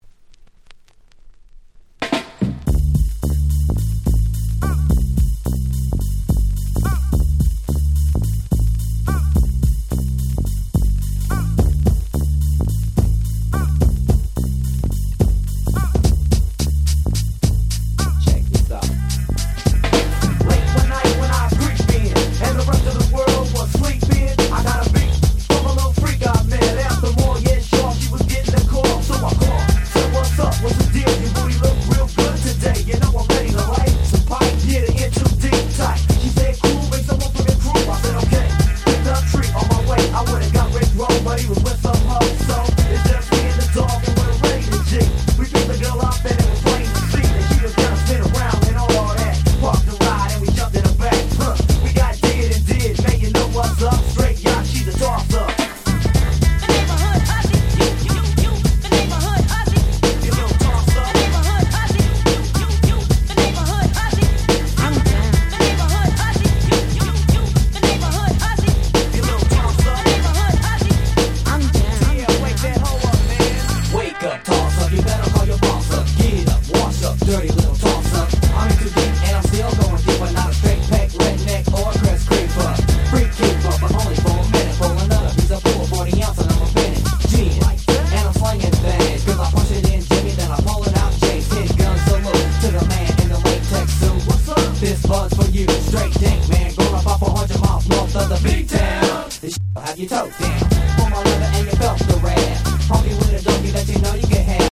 ウィキードミックス 90's Boom Bap ブーンバップ R&B 勝手にRemix 勝手にリミックス ミックス物